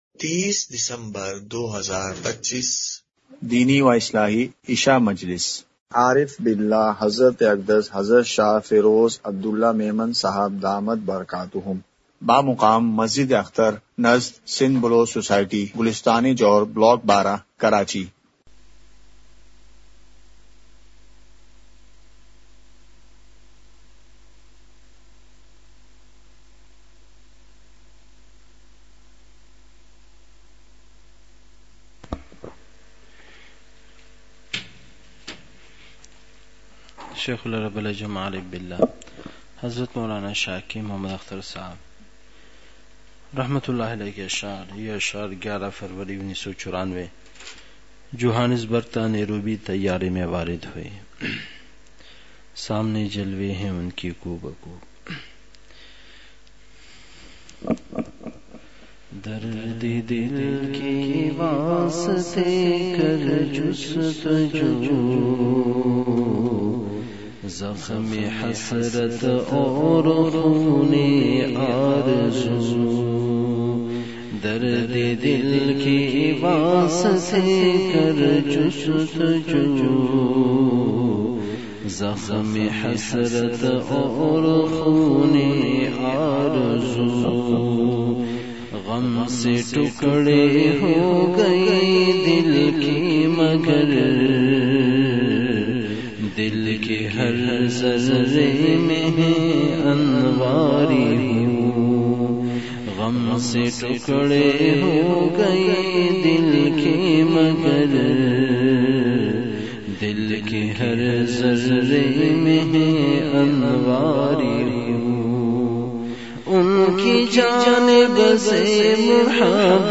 مقام:مسجد اختر نزد سندھ بلوچ سوسائٹی گلستانِ جوہر کراچی
07:31) بیان کے آغٓاز میں اشعار کی مجلس ہوئی۔۔